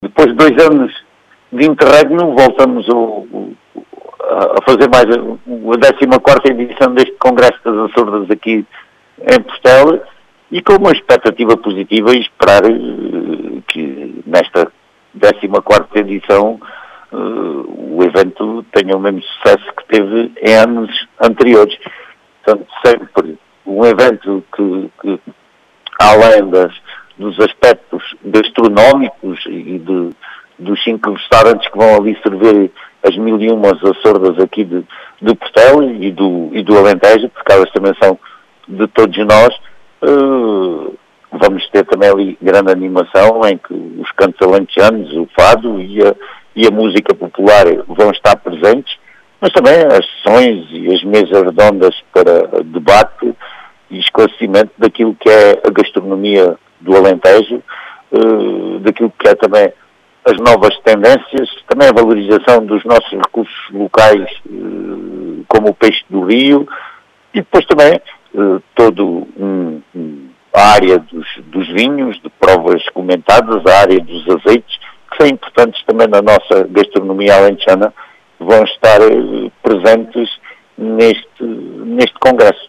As explicações são de José Manuel Grilo, presidente da Câmara Municipal de Portel.
Jose-Manuel-Grilo.mp3